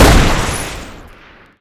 shoot1.wav